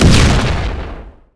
GrenadeExplode.wav